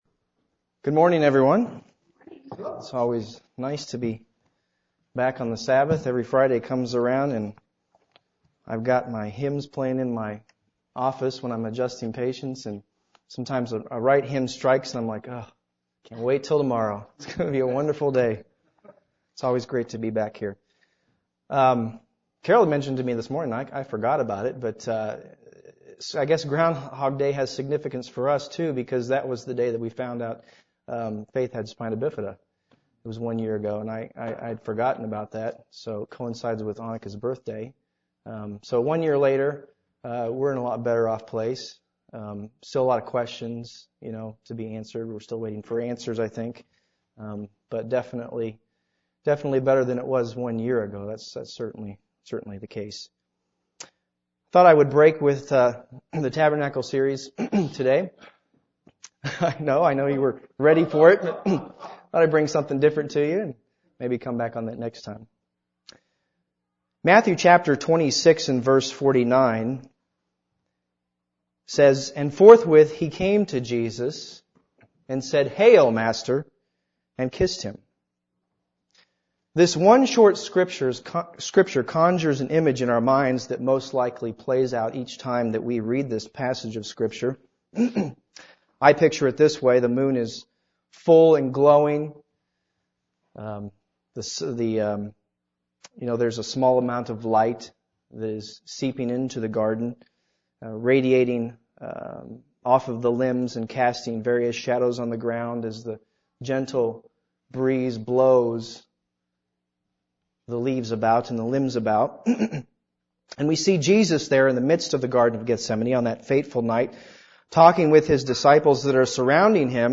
Why didn't they know the heart that Judas had? This sermon reflects upon the life of Judas and serves as a serious warning for all followers of Christ.
Given in Columbia - Fulton, MO